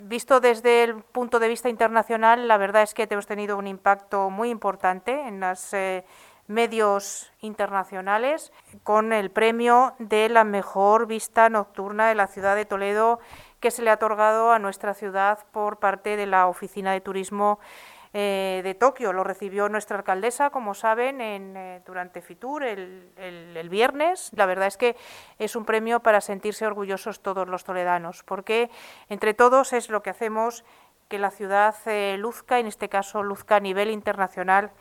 Mar Álvarez, que ha ofrecido este lunes una rueda de prensa para hacer balance de la presencia de Toledo en Fitur, ha señalado que ha sido “un Fitur 10 para un año de 10” con el convencimiento de que 2022 sea el año de la recuperación definitiva y la vuelta a la normalidad de los viajes nacionales e internacionales.